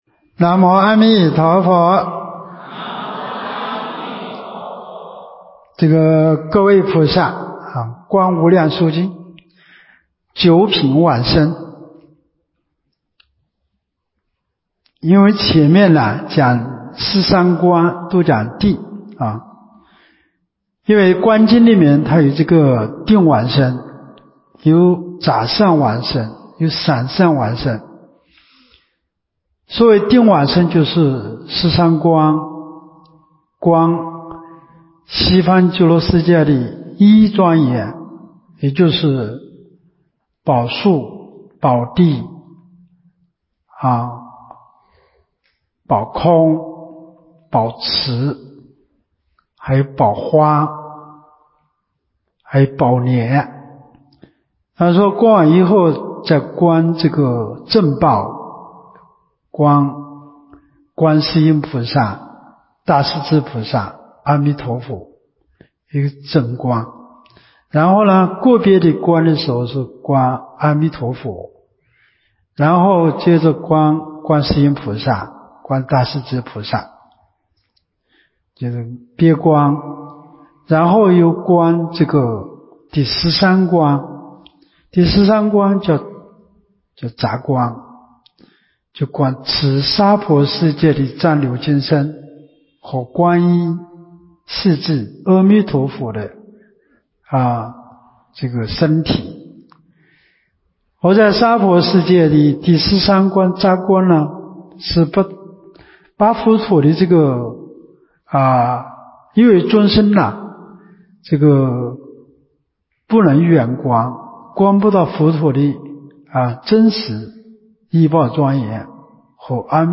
24年陆丰学佛苑冬季佛七（四）